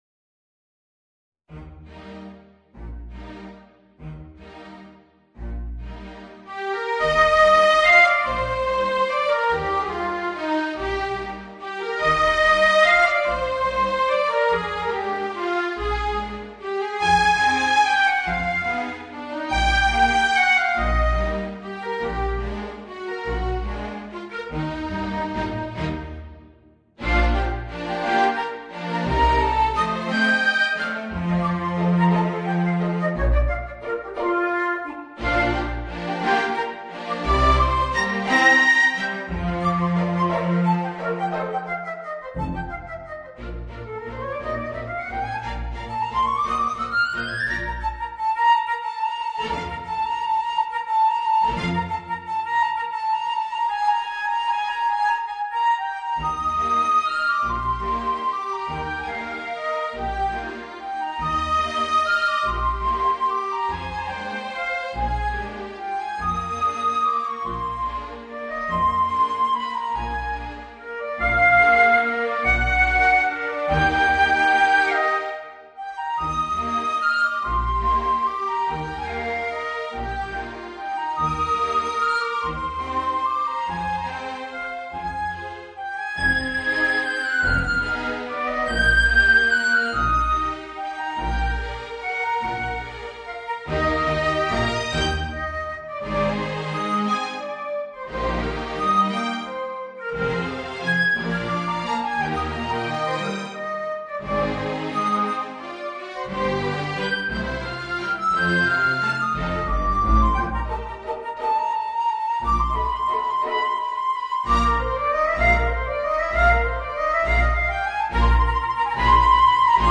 Voicing: Viola and String Orchestra